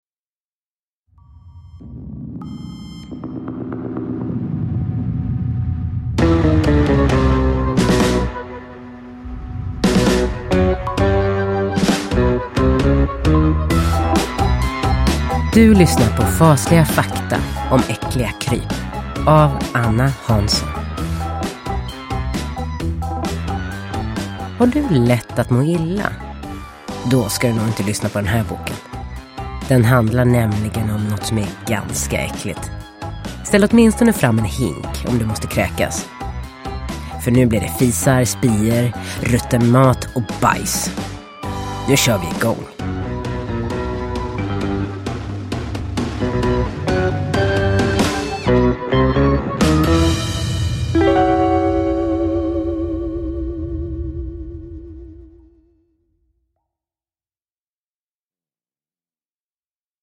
Fasliga fakta om äckliga kryp – Ljudbok – Laddas ner